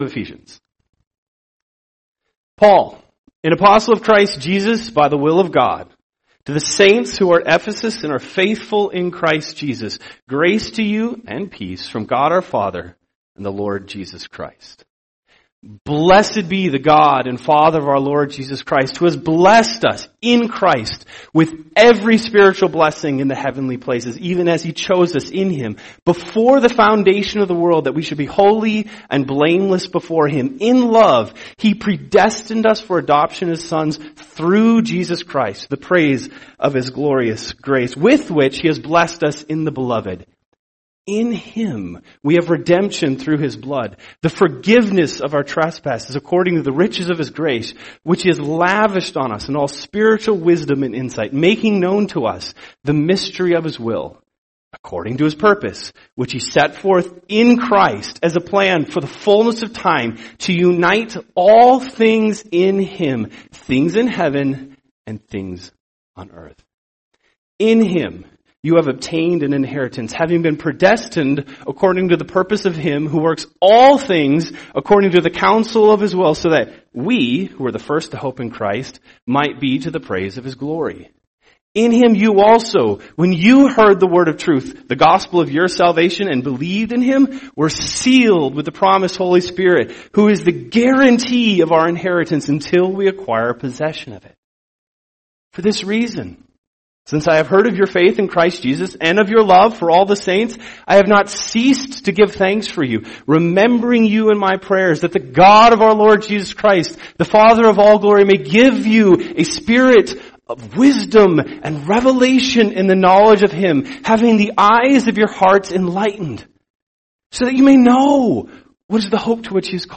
Recitation of Ephesians